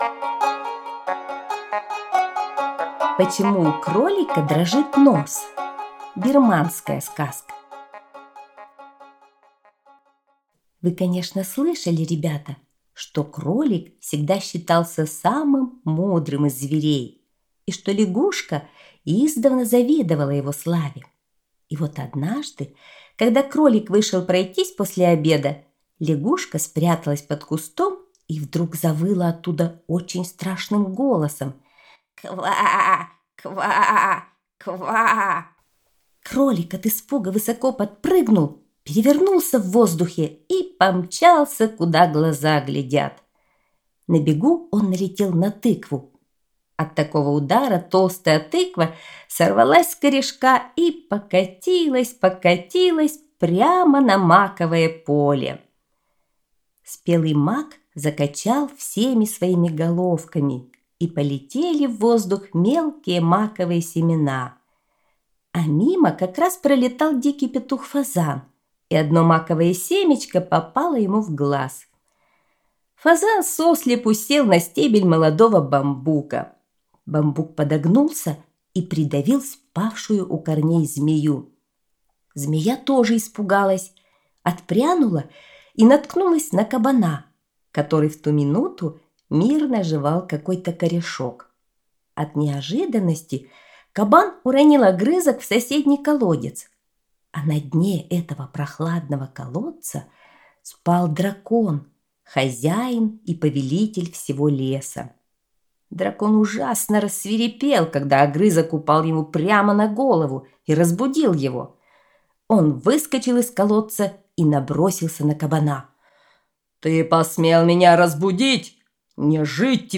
Бирманская аудиосказка